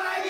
crowdDon2.wav